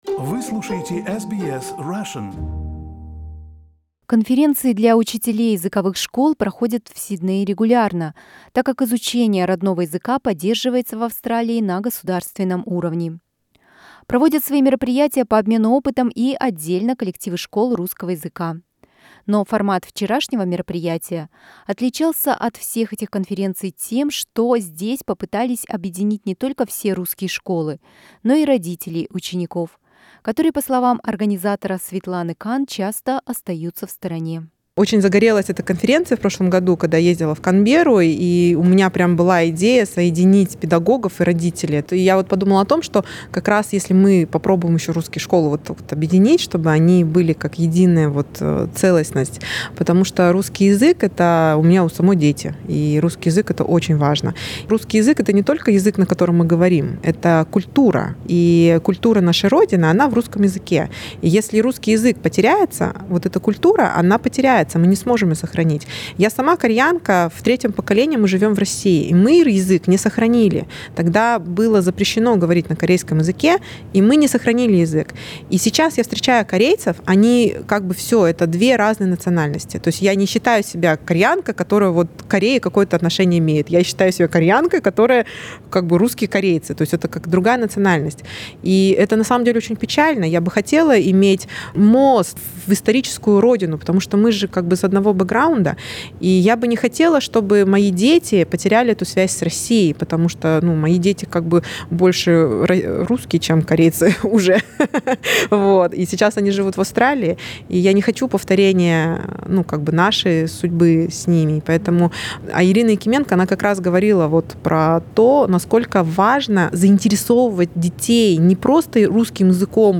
Вчера в Сиднее прошла конференция «Наши дети»: для педагогов, преподающий русский язык, и родителей детей-билингвов. Интересные доклады, презентации, дискуссии, награждение "Учителя года" и обмен опытом в ходе круглого стола – об этих и других подробностях конференции - в нашем репортаже.